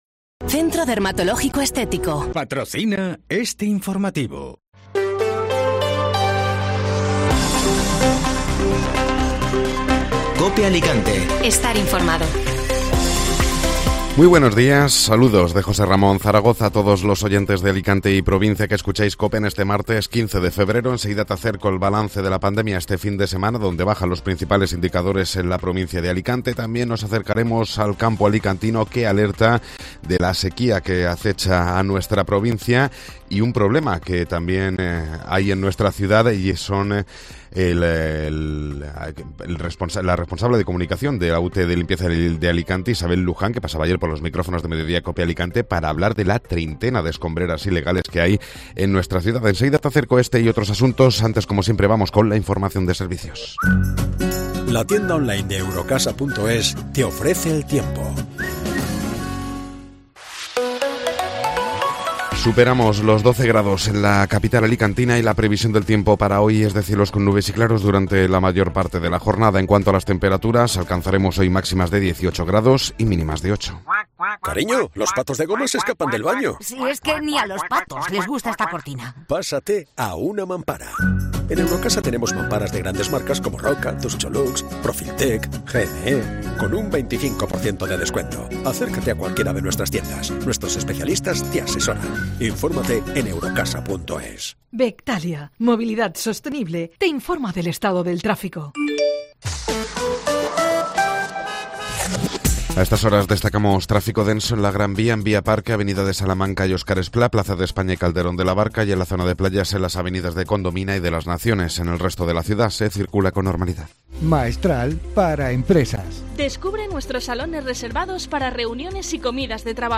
Informativo Matinal (Martes 15 de Febrero)